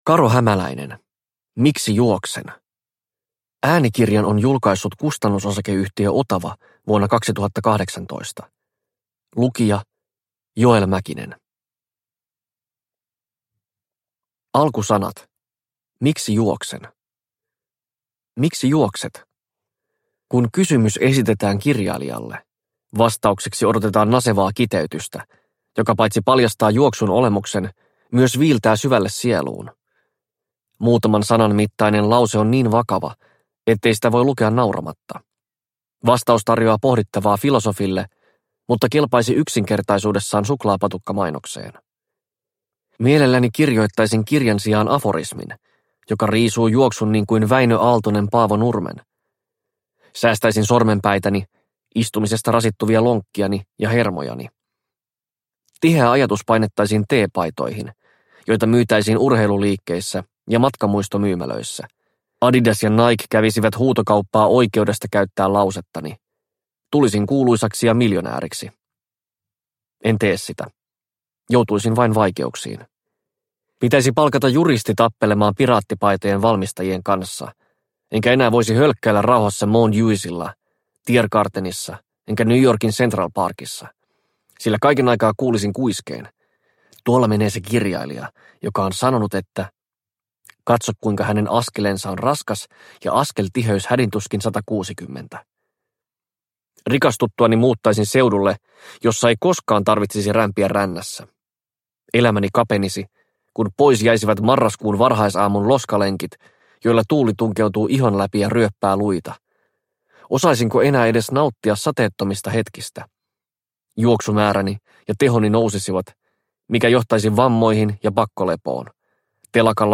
Miksi juoksen – Ljudbok – Laddas ner